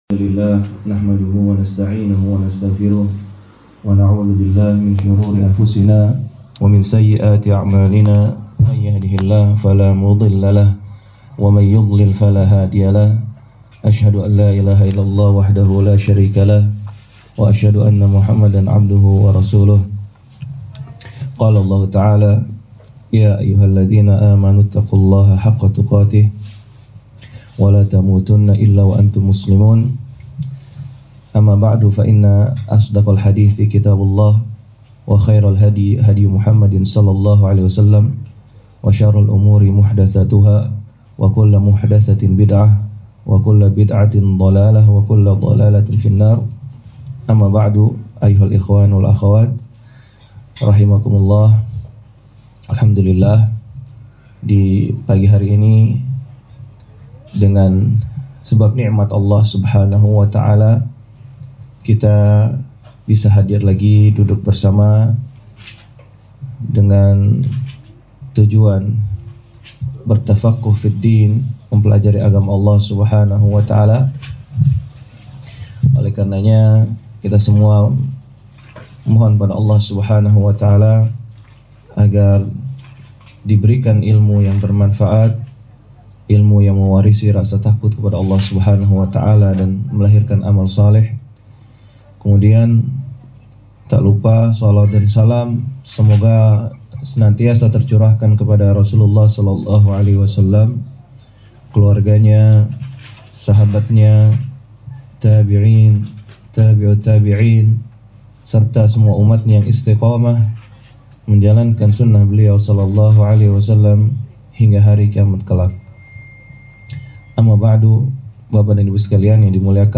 Daurah Umsaeed Jum’at Pagi